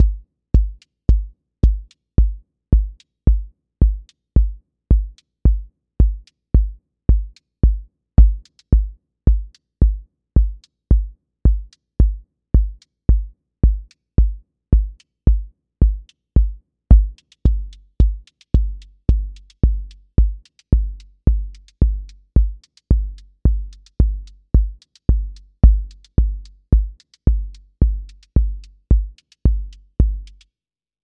QA Listening Test house Template: four_on_floor
house four on floor 30s
• voice_kick_808
• voice_hat_rimshot
• voice_sub_pulse
• tone_warm_body
• fx_space_haze_light
• motion_drift_slow